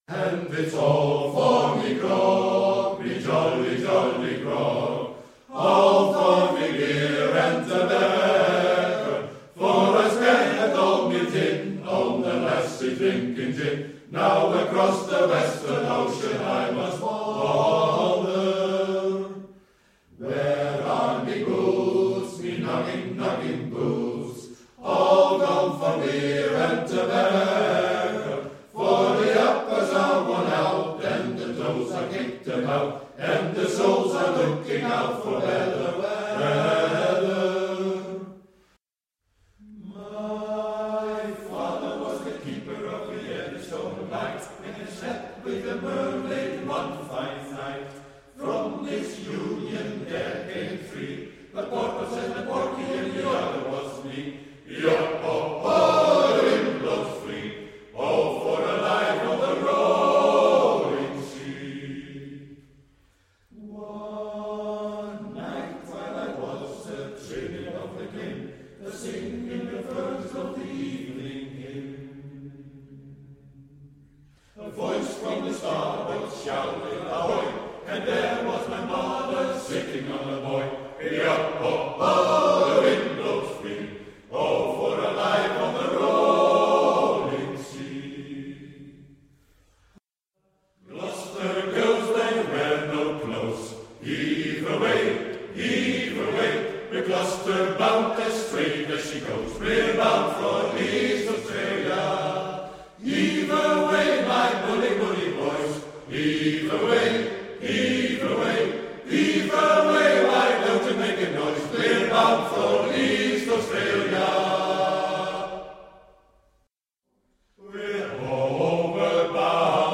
Recueil pour Chant/vocal/choeur - Voix